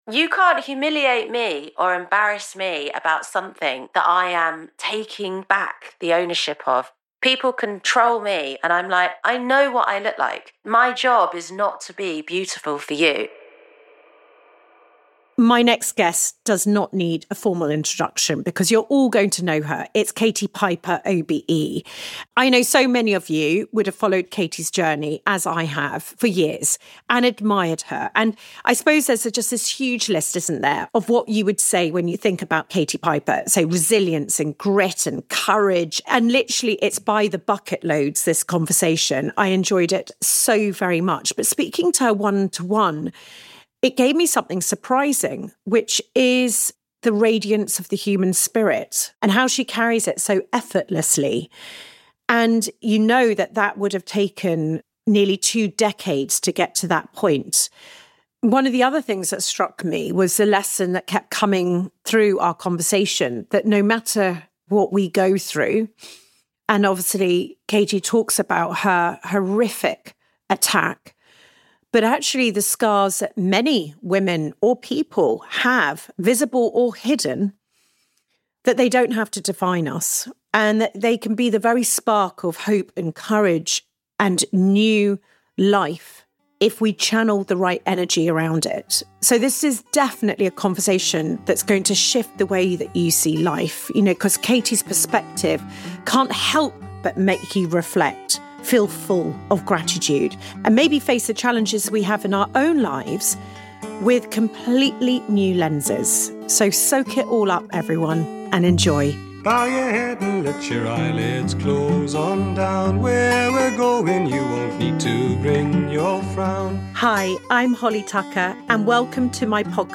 This conversation is a heartfelt celebration of resilience.